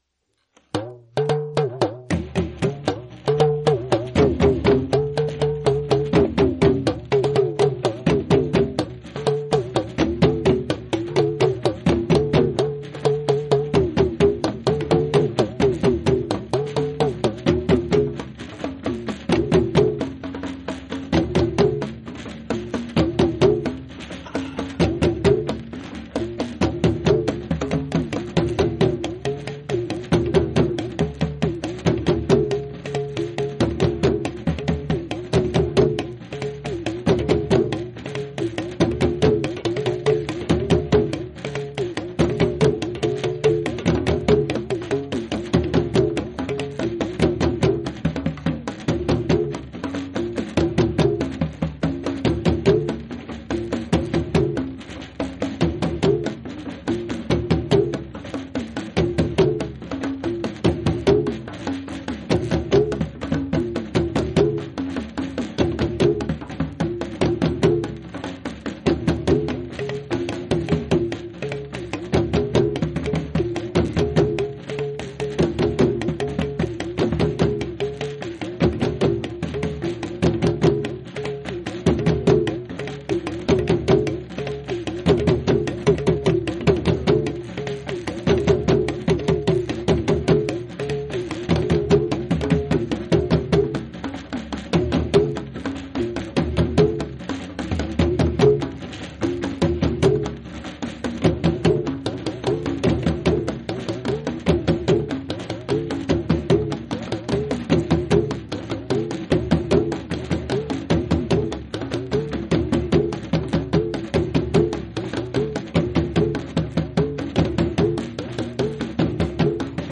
Fire Festival (Nanton 1991) Fire Festival part 1 (47:06) Fire Festival part 2 (16:12) Pan' Dola Yɛliga (2:08)